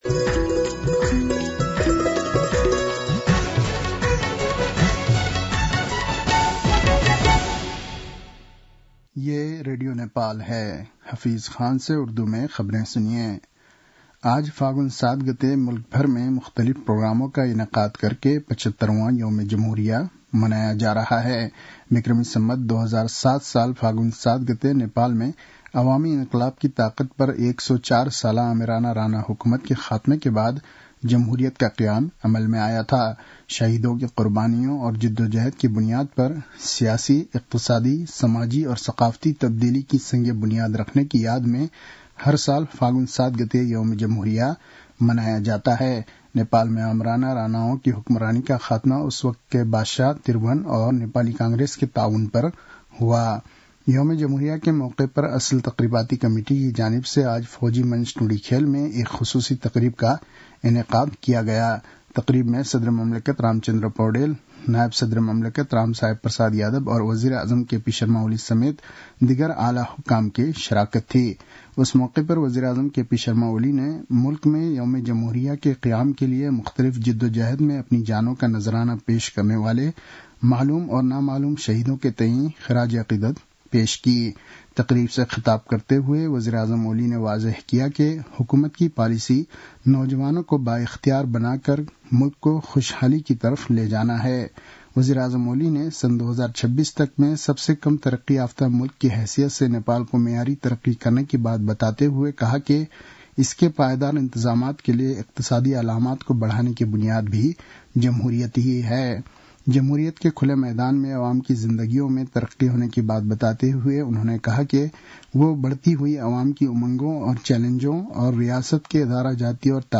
उर्दु भाषामा समाचार : ८ फागुन , २०८१